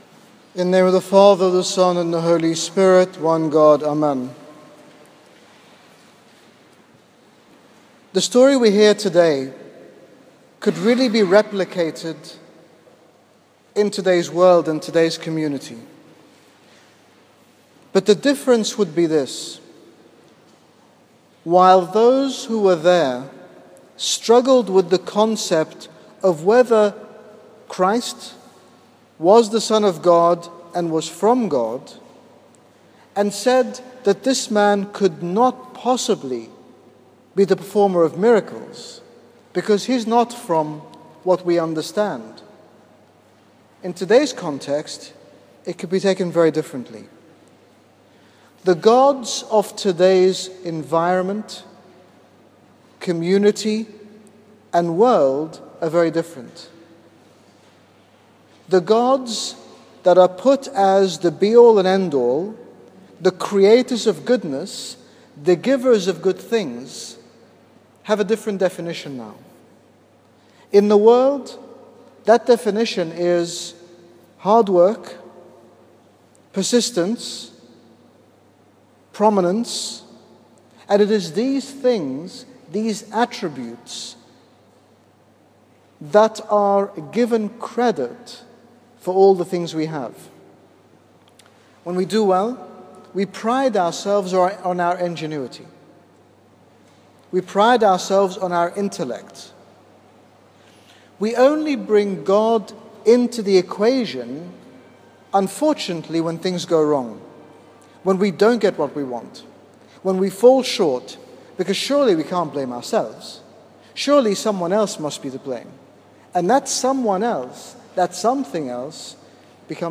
In this short sermon at St Paul Ministry, His Grace Bishop Angaelos, General Bishop of the Coptic Orthodox Church in the United Kingdom, speaks about attributing our gifts and blessings to God, the Origin of all things.